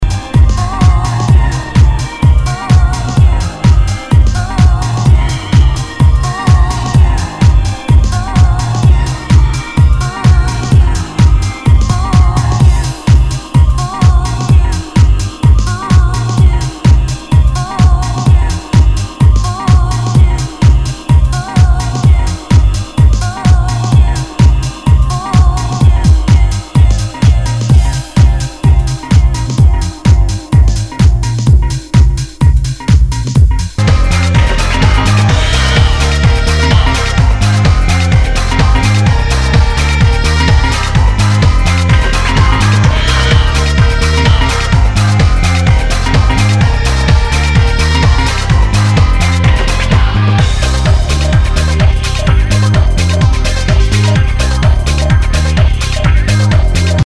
HOUSE/TECHNO/ELECTRO
ナイス！ディープ・ヴォーカル・ハウス！